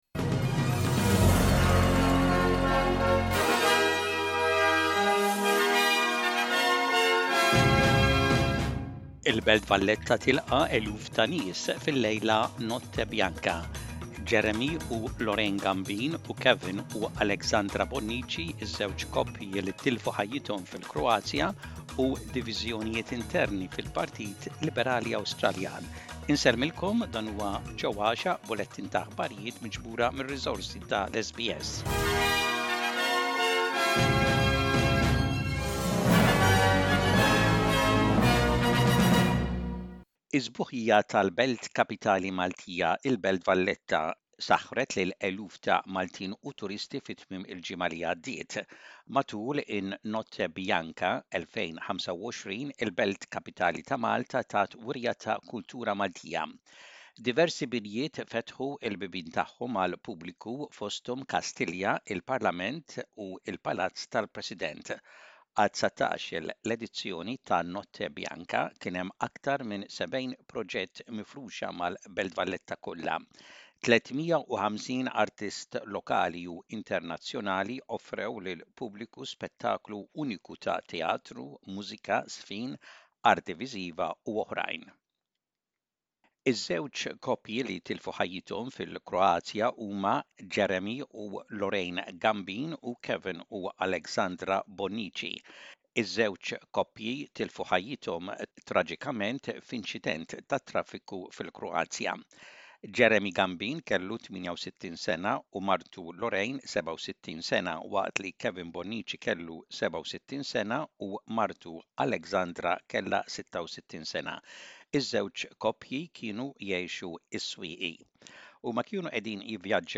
SBS Maltese News: 07.10.25